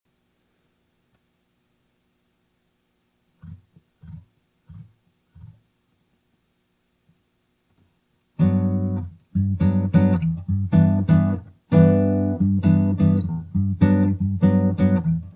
these are MP3s of silence then a short guitar track…I dont know how to send you a picture of the wave form...if you could explain that to me it would be helpful..basically there is hum on my recordings that Im almost positive are internal…I turn off everything in the room when recording including the cooling pad under the computer